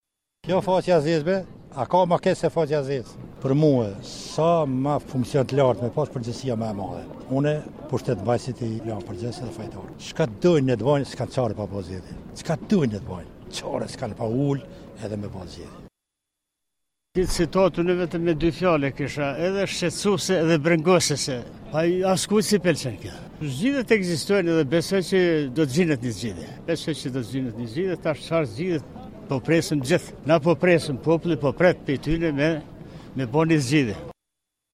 Qytetarët komentojnë bllokimin e Kuvendit